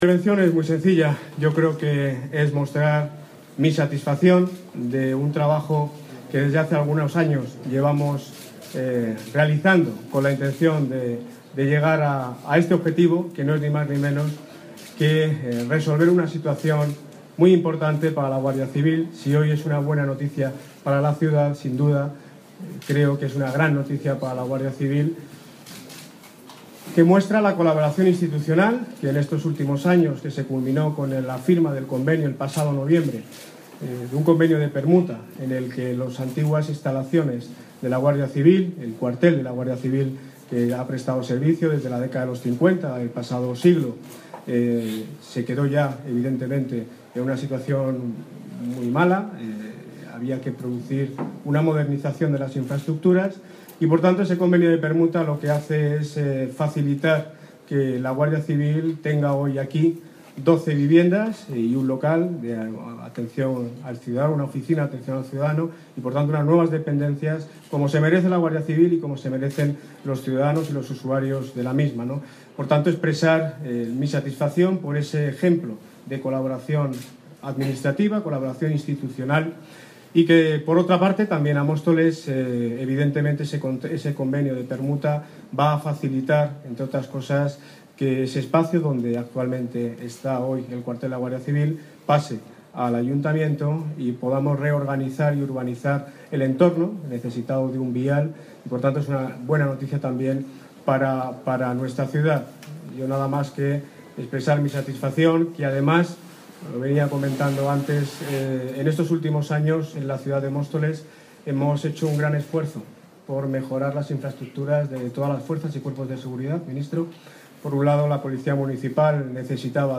Audio - Daniel Ortiz (Alcalde de Móstoles) Sobre dependencias guardia civil